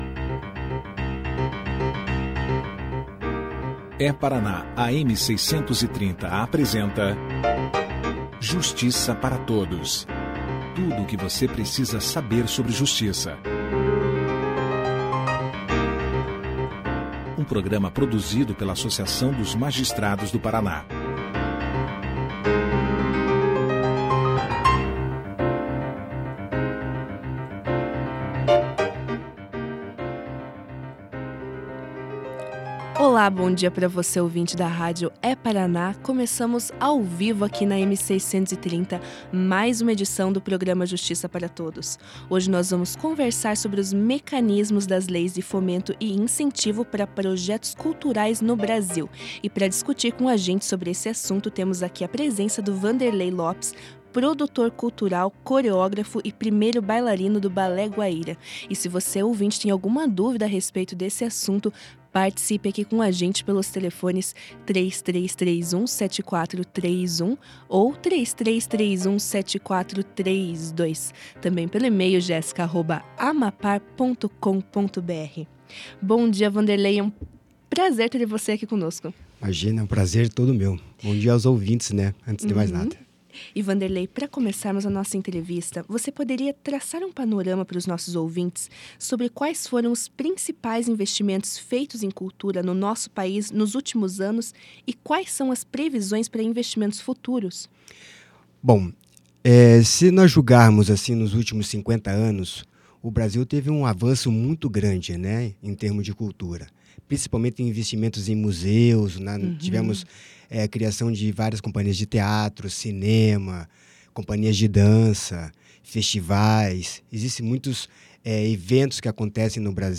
Clique aqui e ouça a entrevista do coreógrafo e produtor musical